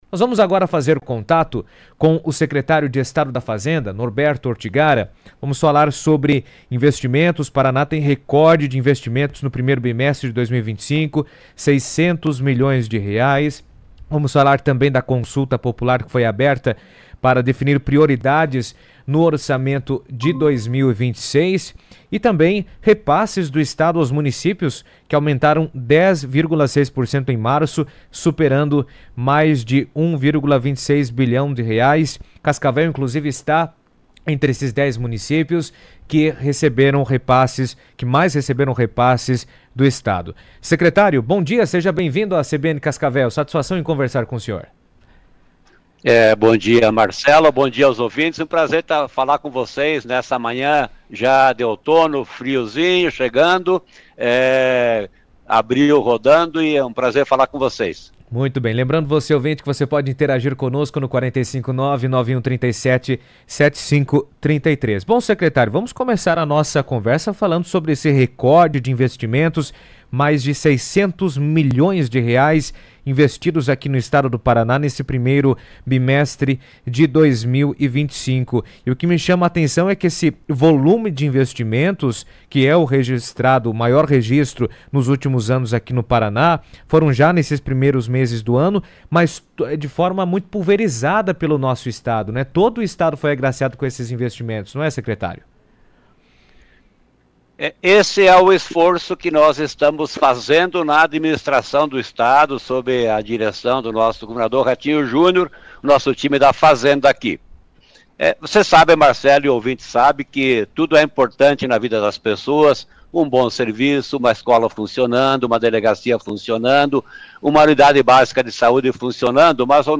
O Secretário de Estado da Fazenda, Norberto Ortigara, participou do CBN Cascavel nesta segunda (07) e entre outros assuntos, destacou o volume de investimentos realizados pelo Governo do Estado, principalmente, em obras estruturantes na região oeste.